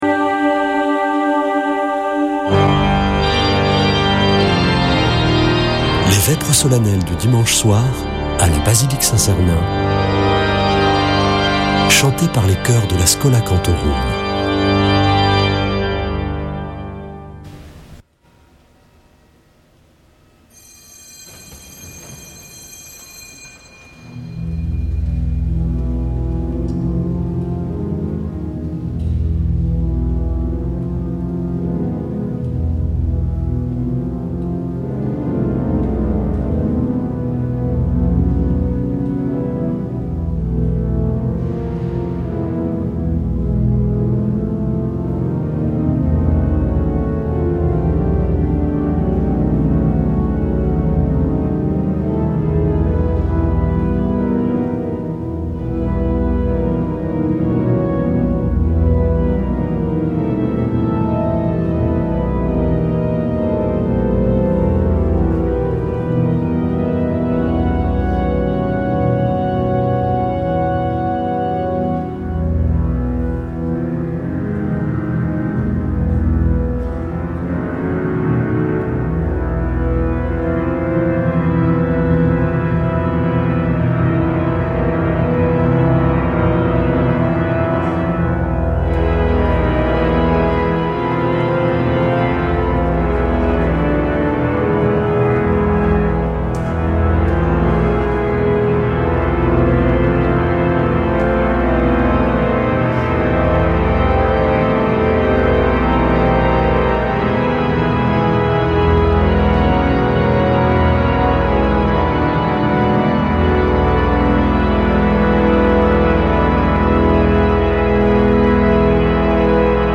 Vêpres de Saint Sernin du 08 juin
Une émission présentée par Schola Saint Sernin Chanteurs